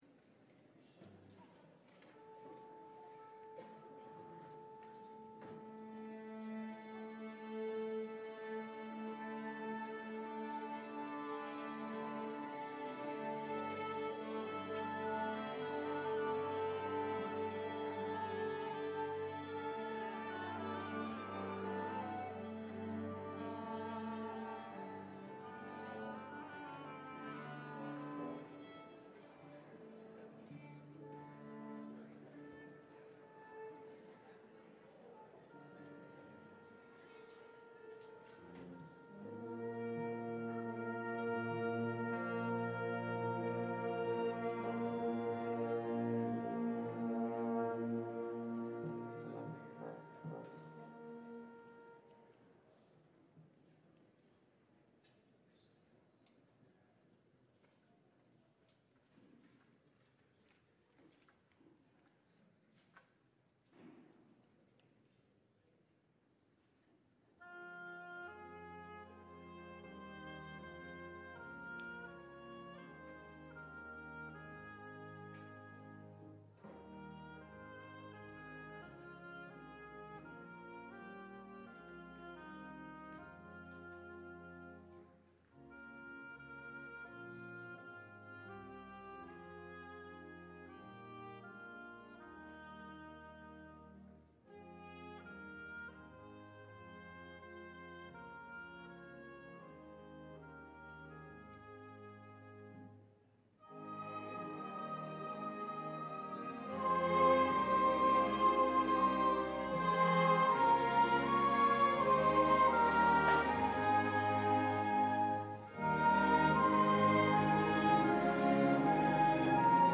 LIVE Evening Worship Service - Christmas Carol Sing
will feature readings and carols for the Christmas season.